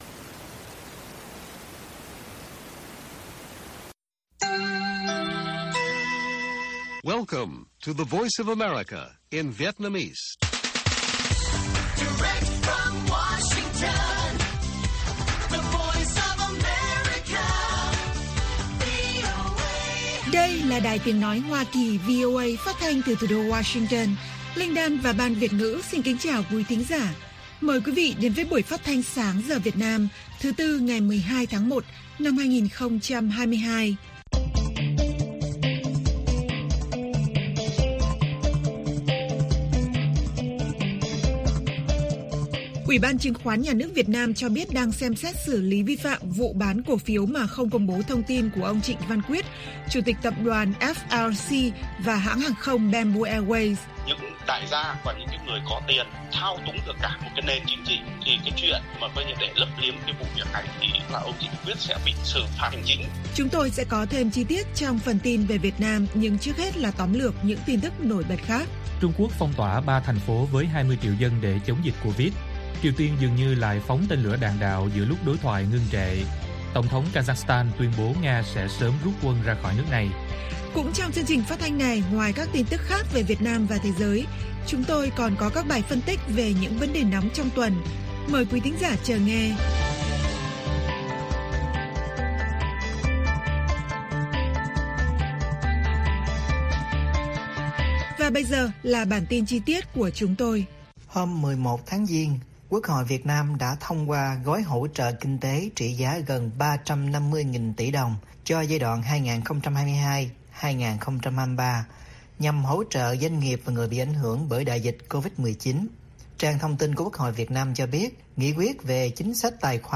Bản tin VOA ngày 12/1/2022